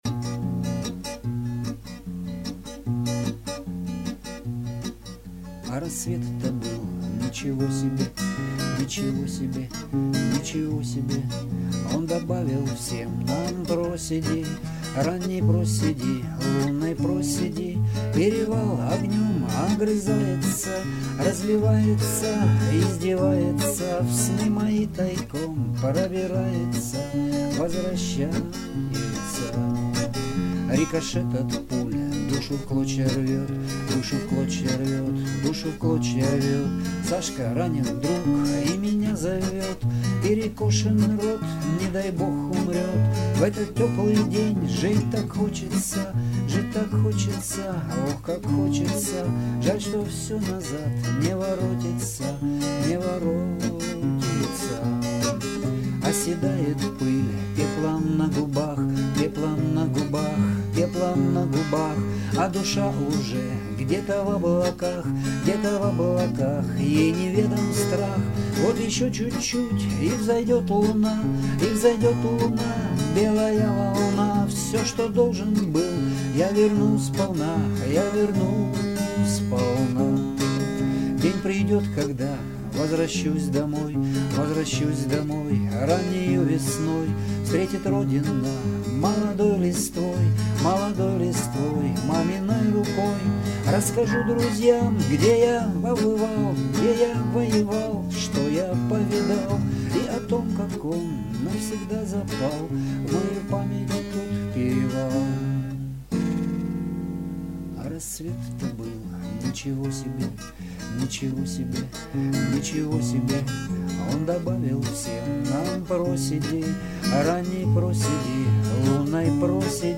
Рубрика: Поезія, Авторська пісня